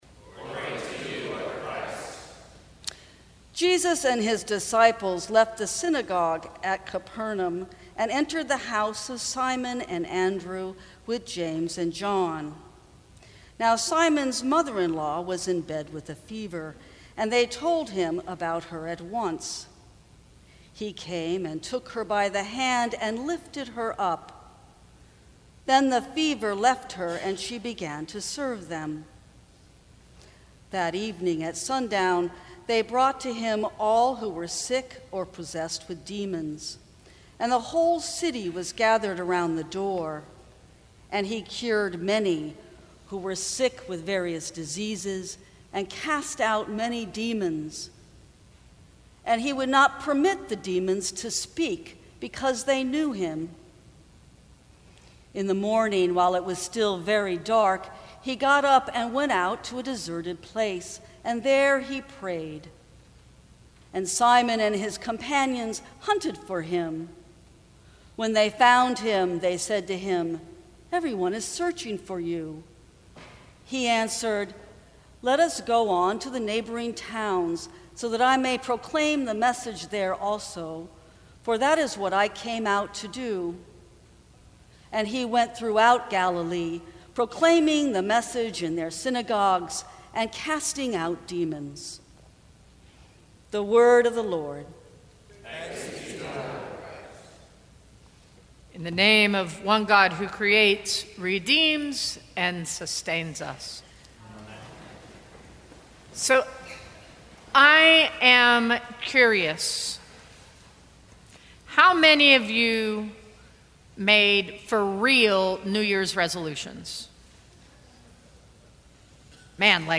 Sermons from St. Cross Episcopal Church Restart Feb 05 2018 | 00:14:41 1x 00:00 / 00:14:41 Subscribe Share Apple Podcasts Spotify Overcast RSS Feed Share Link Embed